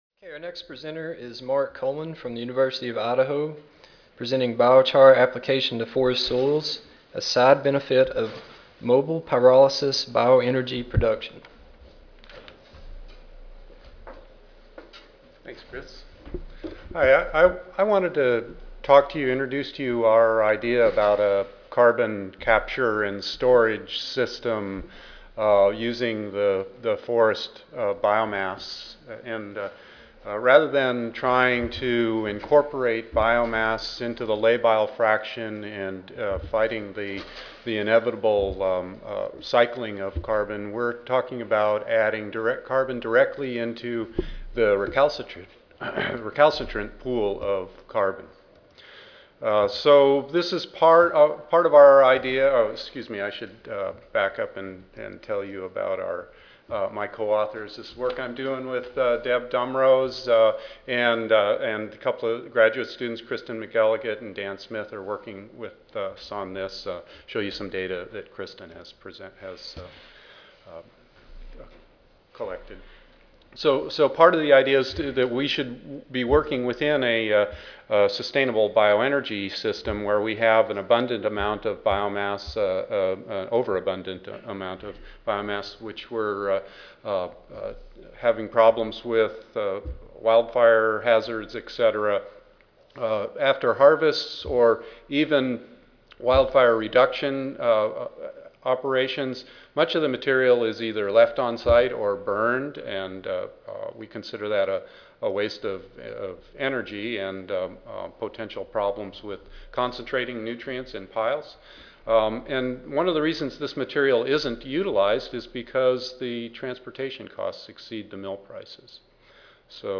Abstract: Biochar Application to Forest Soils: A Side Benefit of Mobile Pyrolysis Bioenergy Production. (2010 Annual Meeting (Oct. 31 - Nov. 3, 2010))